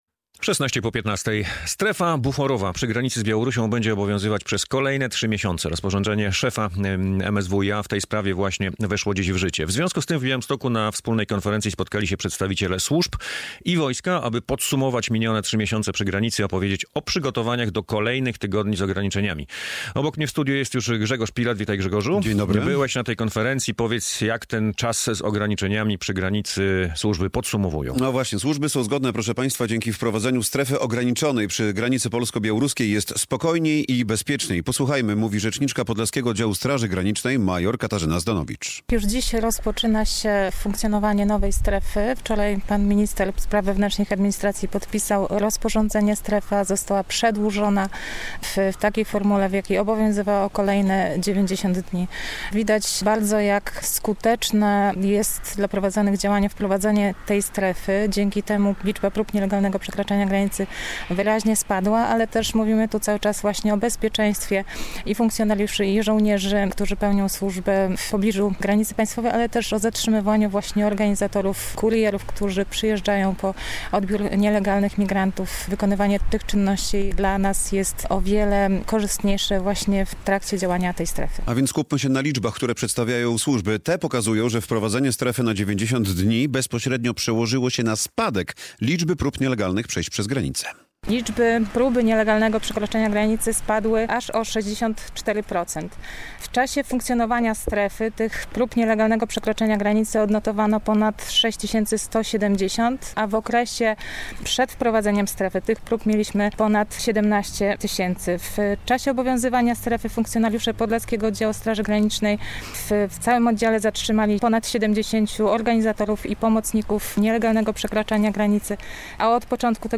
Jak się sprawdza strefa buforowa na granicy - relacja